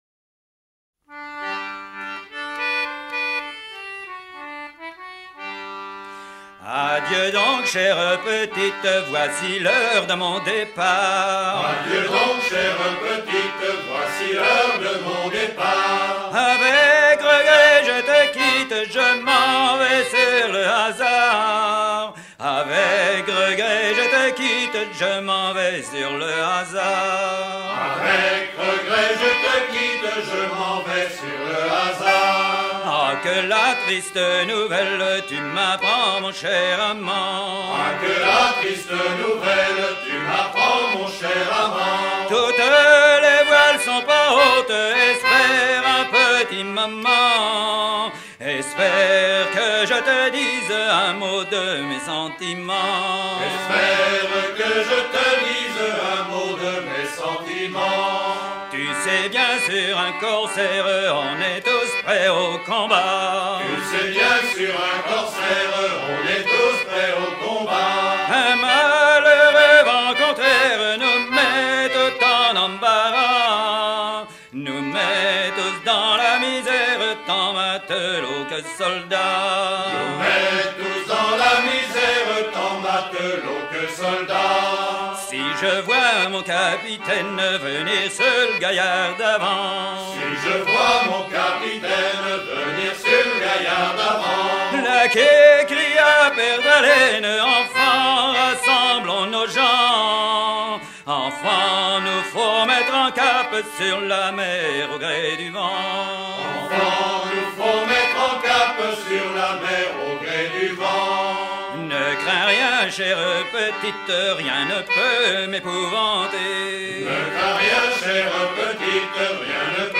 Côte vendéenne
Genre strophique